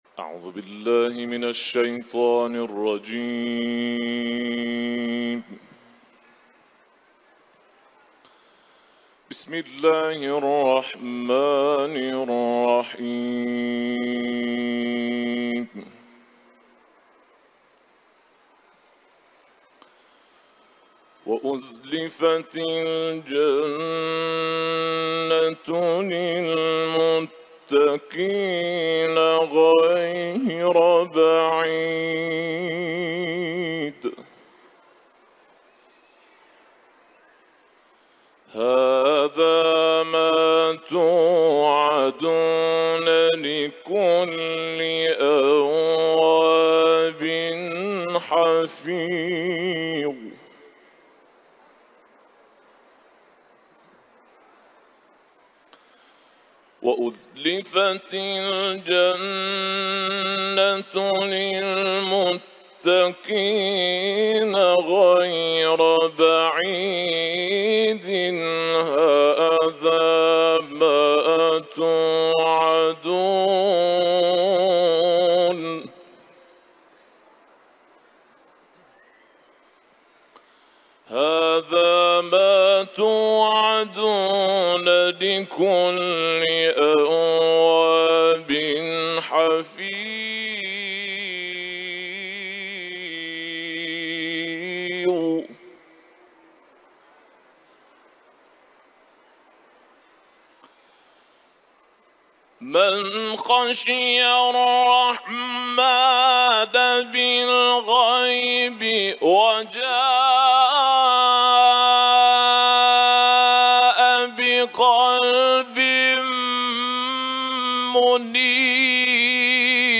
سوره ق ، تلاوت قرآن ، حرم حضرت رضا